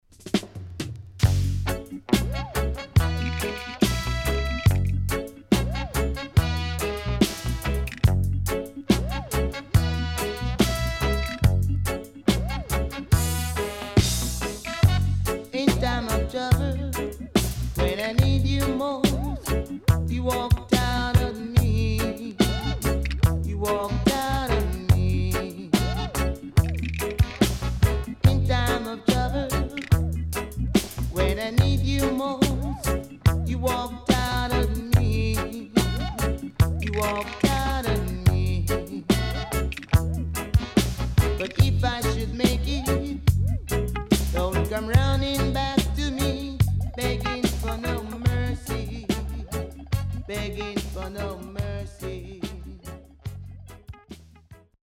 Killer Vocal & Dubwise